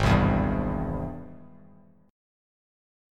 Ab+M7 chord